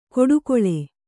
♪ koḍukoḷe